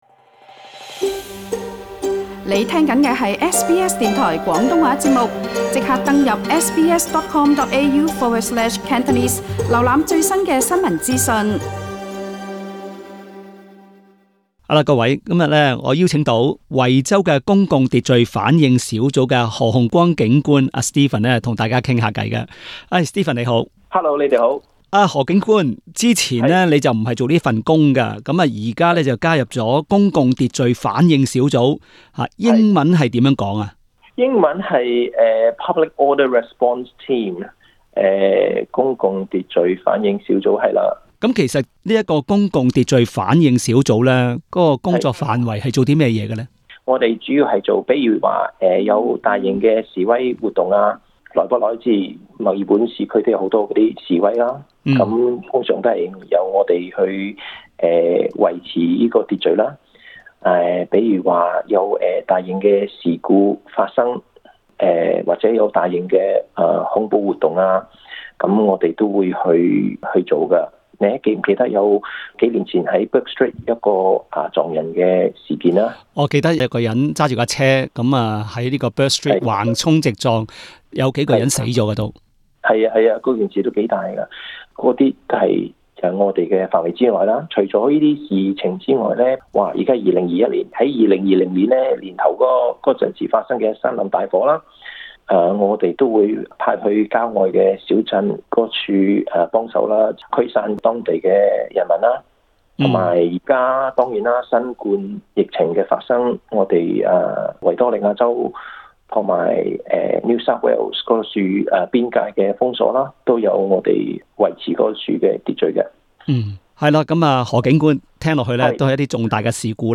社区专访